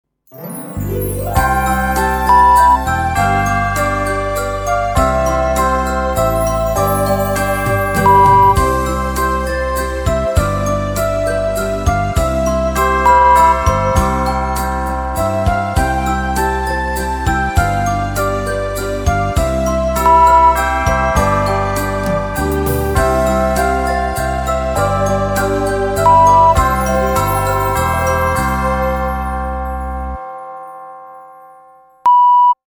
Die Hörprobe enthält ein Wasserzeichen (Störtöne).